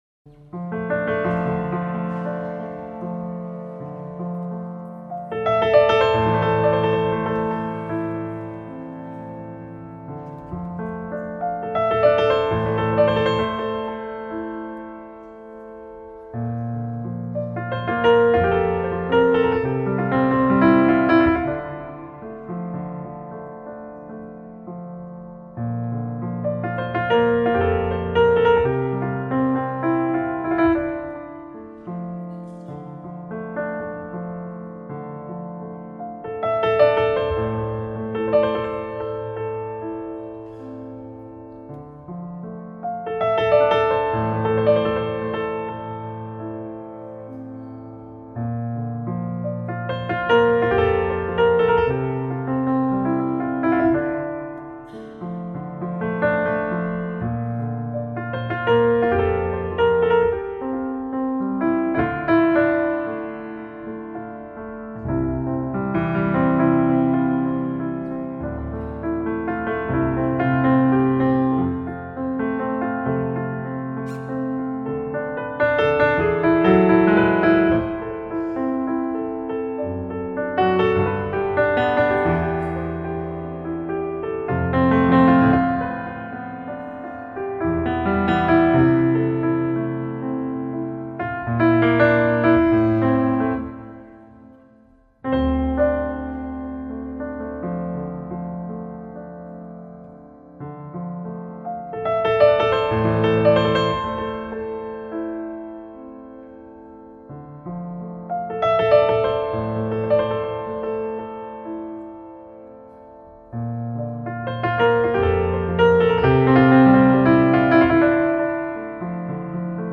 ★ 種類涵蓋爵士、古典、流行、民謠等不同曲風，以最優異的設備、最發燒的手法精心錄製！